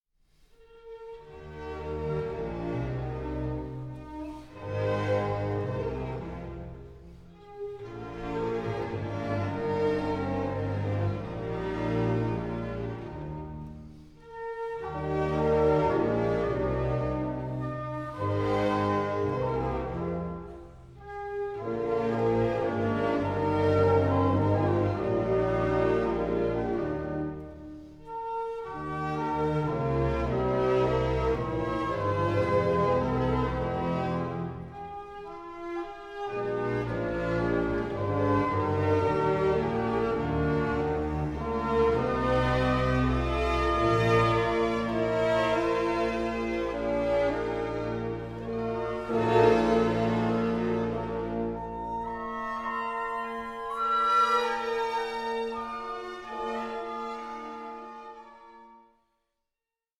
the Fifth sounds Mozartian and cheerful